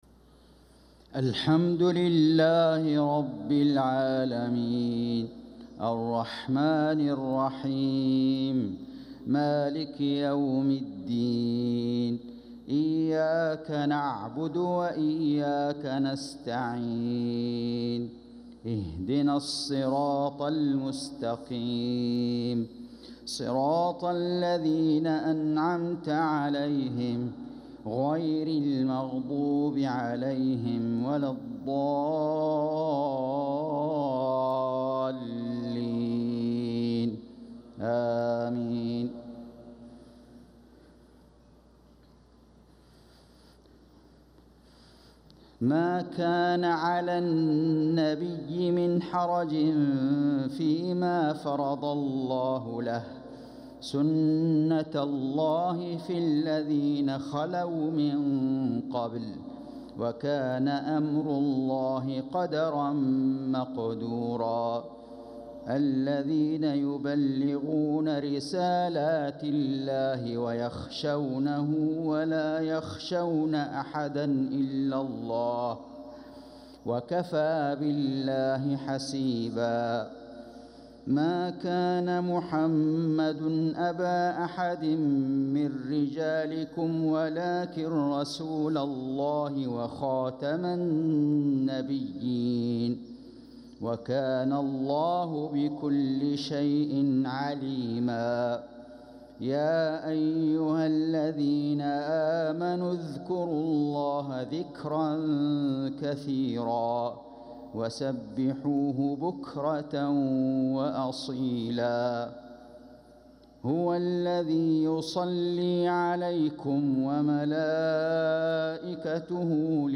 صلاة المغرب للقارئ فيصل غزاوي 8 صفر 1446 هـ
تِلَاوَات الْحَرَمَيْن .